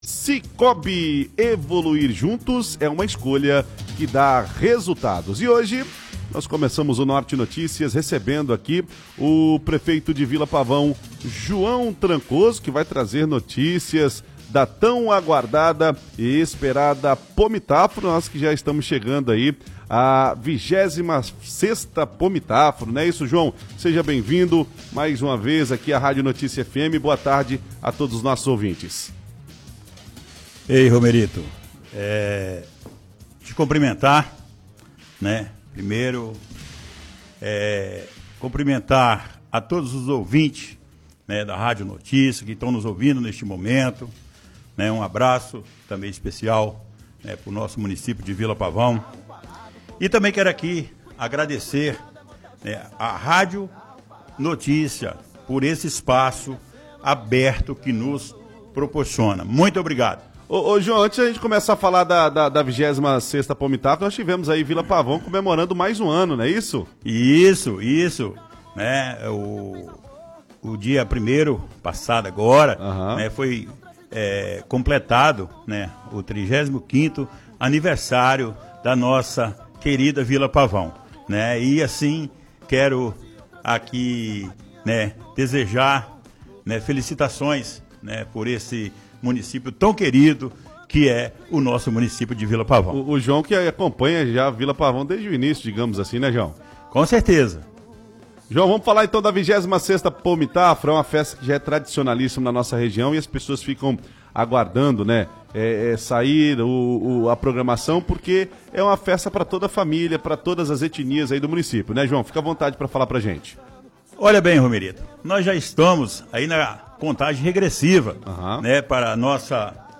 João Trancoso apresenta programação da 26ª Pomitafro em entrevista à Rádio Notícia FM
Nesta quarta-feira (3), o prefeito de Vila Pavão, João Trancoso, participou de uma entrevista ao vivo na Rádio Notícia FM, onde apresentou os destaques da 26ª edição da Pomitafro e relembrou a comemoração dos 35 anos de emancipação política do município, celebrada no dia 1º de julho.